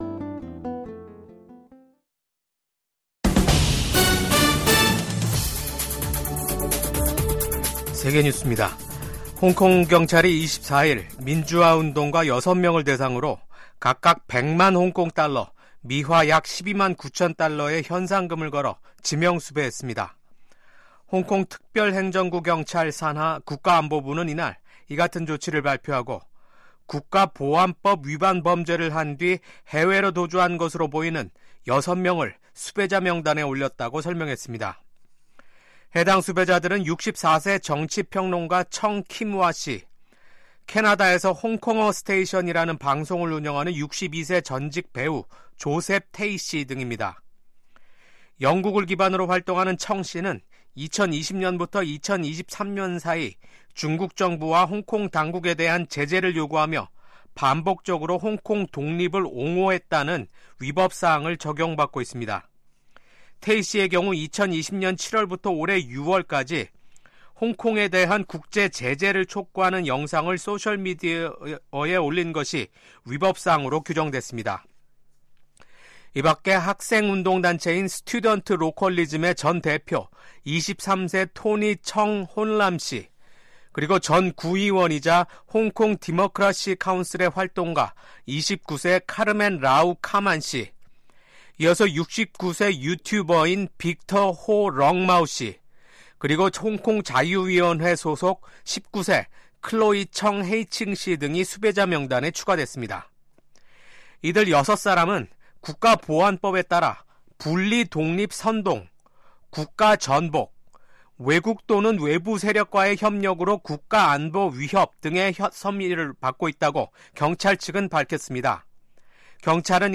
VOA 한국어 아침 뉴스 프로그램 '워싱턴 뉴스 광장'입니다. 러시아 당국은 내년 5월 전승절 행사에 북한 군이 참여할 수 있다고 밝혔습니다. 미국과 한국 정부가 최근 한국의 ‘비상계엄’ 사태 등으로 연기됐던 주요 외교, 안보 일정을 재개하기로 합의했습니다. 미국의 한반도 전문가들은 한국에서 진보 정부가 출범하면 ‘진보적 대북 정책’을 실현하기 위해 동맹을 기꺼이 희생할 것이라고 전망했습니다.